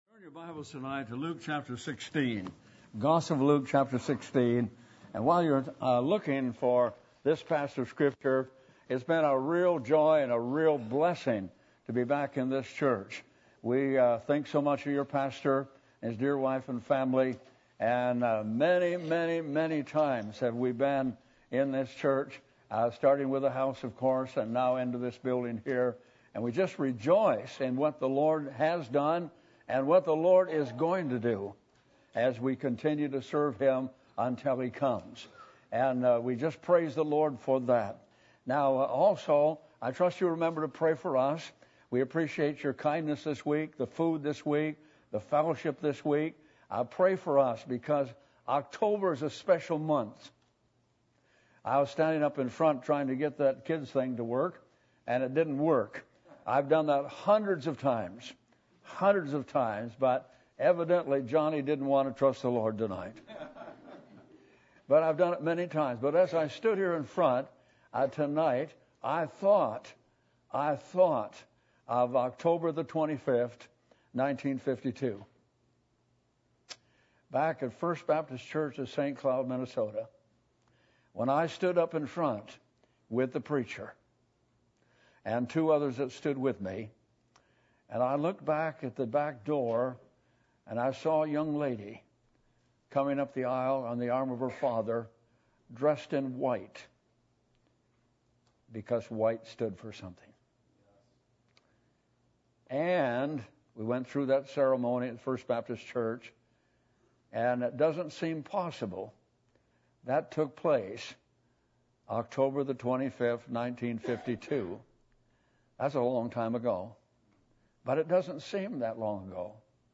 Passage: Luke 16:19-31 Service Type: Revival Meetings %todo_render% « Rich Towards God The Attributes Of God